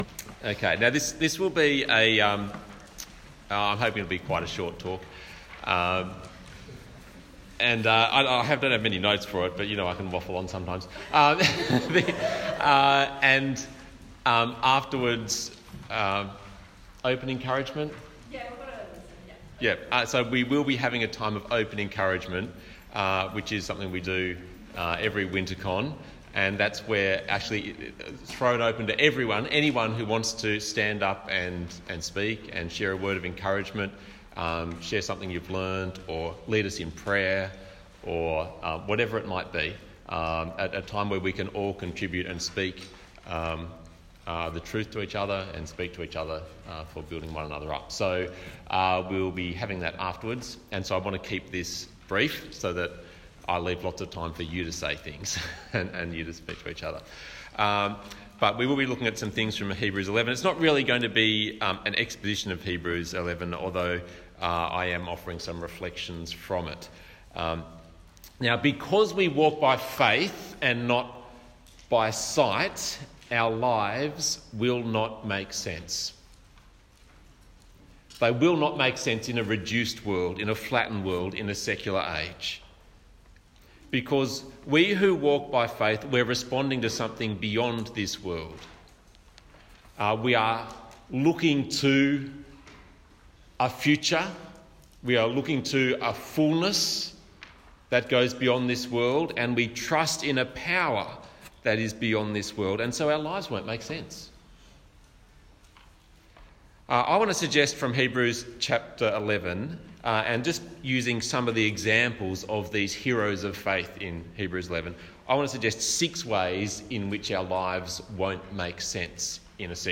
Talk Type: Wintercon